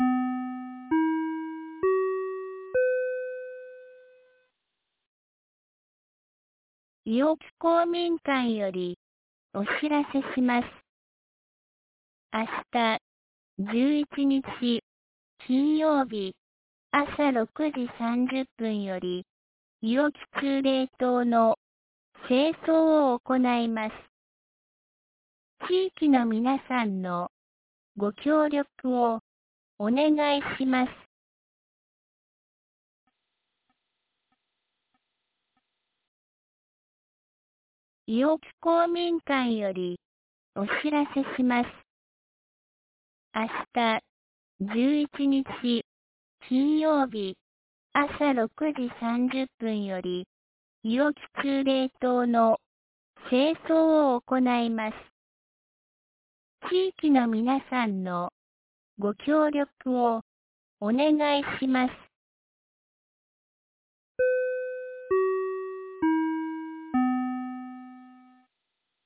2023年08月10日 17時11分に、安芸市より伊尾木、下山へ放送がありました。